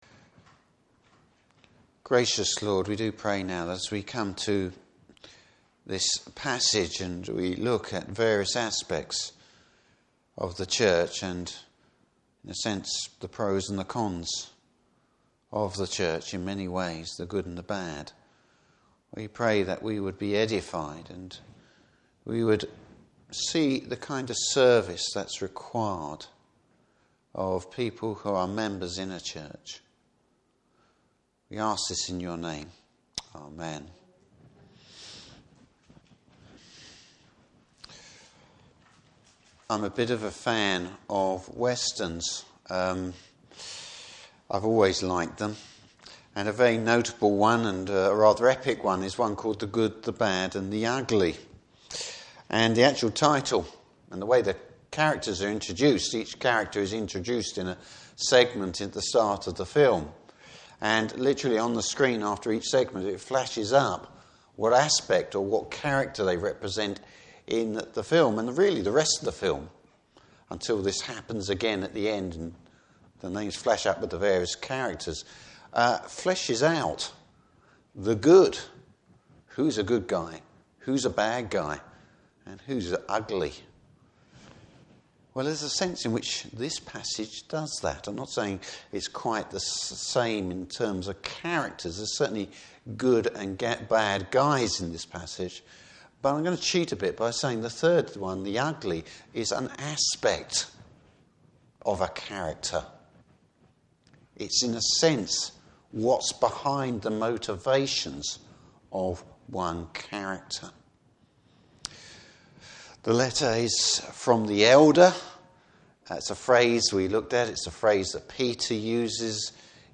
Service Type: Evening Service Bible Text: 3 John.